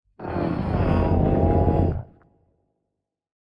Minecraft Version Minecraft Version snapshot Latest Release | Latest Snapshot snapshot / assets / minecraft / sounds / mob / warden / ambient_12.ogg Compare With Compare With Latest Release | Latest Snapshot